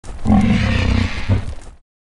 Вы можете слушать и скачивать рычание, крики, шум крыльев и другие эффекты в высоком качестве.
Звук грифона з Варкрафт 3